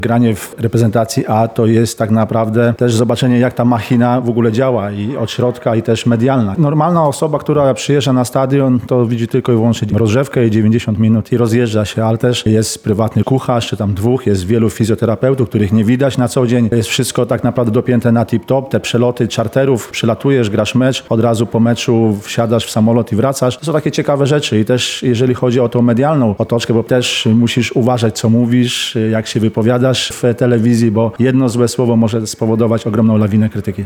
Spotkanie zorganizowano w Centrum Historii Sportu w Lublinie.